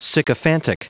Prononciation du mot sycophantic en anglais (fichier audio)
Prononciation du mot : sycophantic